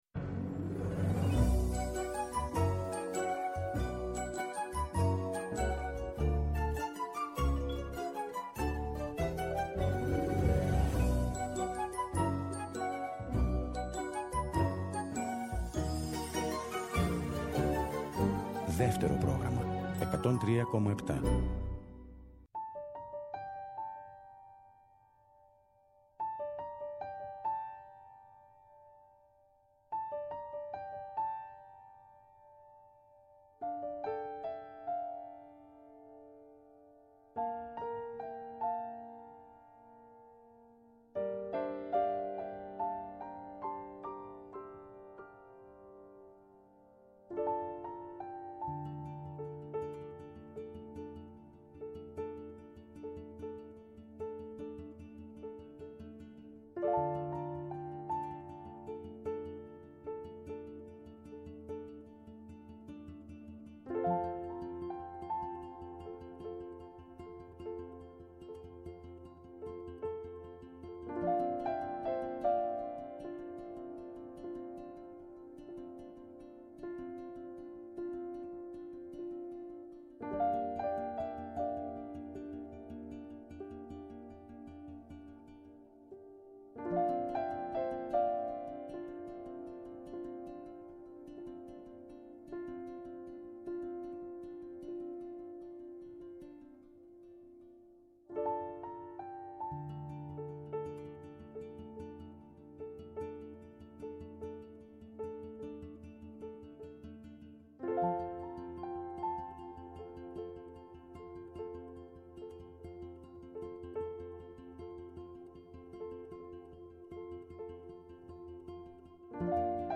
Βόλτες στις μελωδίες, τους ήχους και τους στίχους από όλες τις εποχές του ελληνικού τραγουδιού, διανθισμένες με παρουσιάσεις νέων δίσκων, κινηματογραφικών εντυπώσεων, αλλά και ζεστές κουβέντες με καλλιτέχνες από τη θεατρική επικαιρότητα.